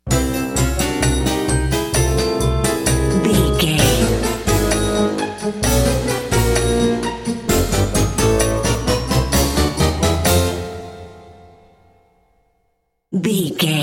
Aeolian/Minor
orchestra
percussion
silly
circus
goofy
comical
cheerful
perky
Light hearted
quirky